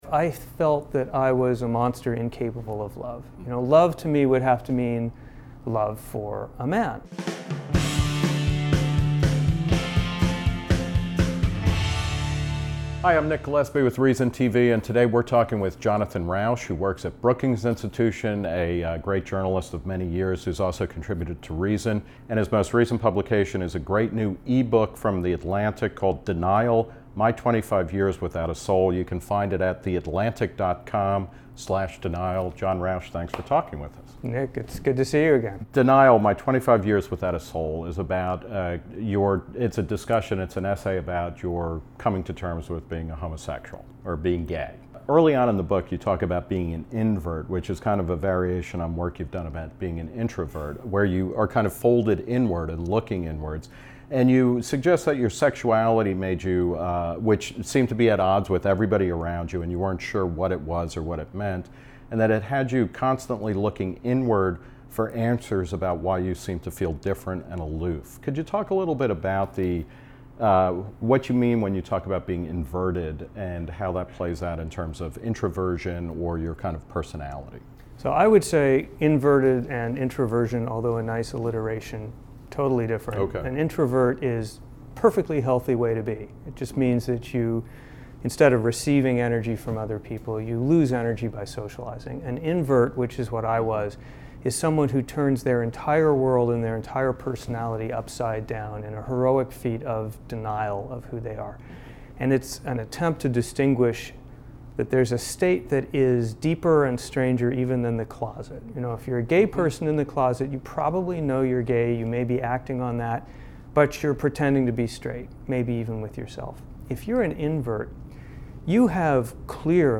Denial: 25 Years Without a Soul - Q/A with Jonathan Rauch
Gillespie sat down with Rauch to talk about Denial, his first crush and sexual awakening, and his eventual marriage.